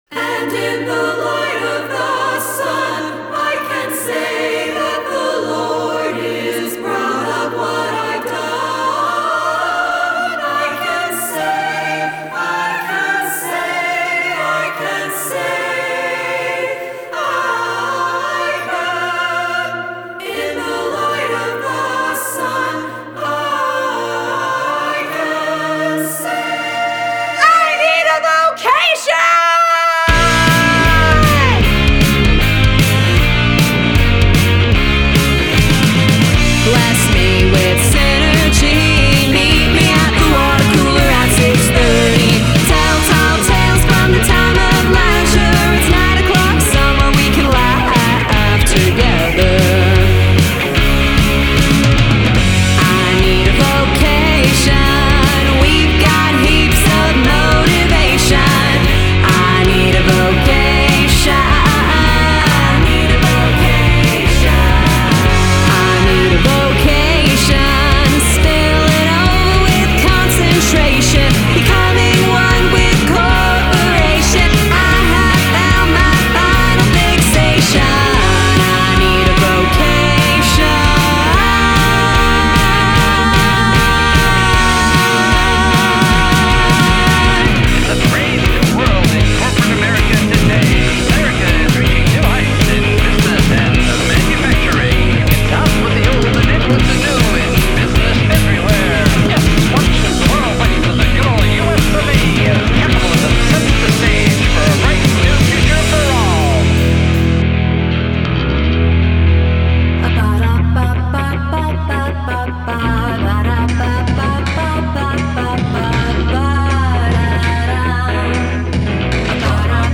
Artist Name: Meteor Street   Title: I Need a Vocation   Genre: rock   Rating:
DAW – Pro Tools 2023; Computer – “Trashcan” Mac Pro; Display/Control Surface – Slate Raven MTi (x2); Interface – Slate VSR8; Guitar – Les Paul and Fender Telecaster; Bass – ‘70s Music Man Stingray; Guitars and bass recorded direct processed with IK Multimedia AmpliTube 5; Drums – PDP MX Series Kit with Zildjian A Cymbals; Microphone – Slate Modeling Mics; Plugins – Slate, Waves and IK Multimedia; Monitors – Kali LP-6; Headphones – AKG
It’s a song with interesting lyrics, distorted guitars, girl group harmony and a healthy dose of fun.
Though any song with subject matter that includes the evolution of the workforce and mentions both the corporate world and work-from-home culture sounds like the sort of thing that Midnight Oil or Rage Against The Machine would write, “I Need a Vocation” sounds happy with a bouncy summery vibe.
The guitars are big and dirty, the bass growls and the drums drive the groove.
The two artists and the mix engineer accurately captured the vibe of a small choir singing in a large space.
The slight differences in how the two guitarists play helps with that bigness, as does the innate tonal differences between the Humbuckers on a Les Paul and the single coil pickups on a Tele.